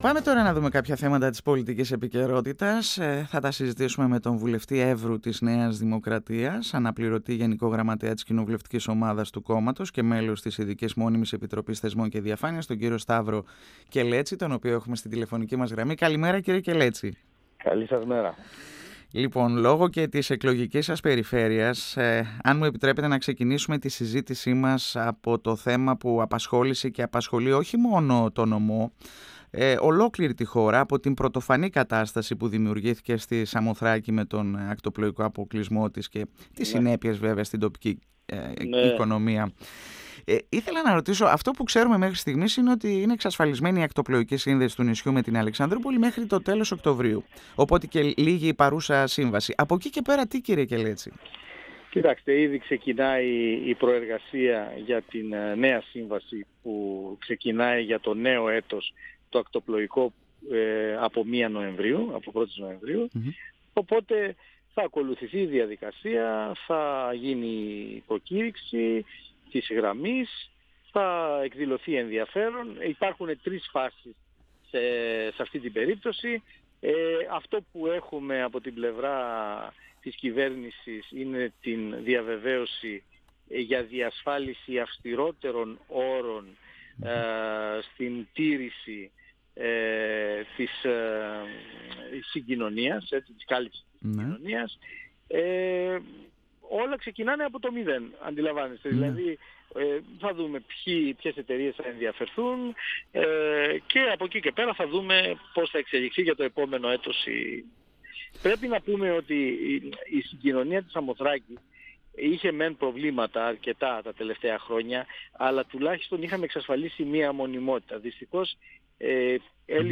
Στη δέσμευση της κυβέρνησης για επιτάχυνση των έργων αποκατάστασης της Σαμοθράκης τόσο στο ζήτημα της ακτοπλοϊκής της σύνδεσης όσο και σε επίπεδο οδικό και αντιπλημμυρικών έργων αναφέρθηκε μιλώντας στον 102 fm της ΕΡΤ3 ο βουλευτής Έβρου της ΝΔ Σταύρος Κελέτσης.